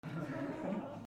小さな笑い
/ M｜他分類 / L50 ｜ボイス
20人前後 D50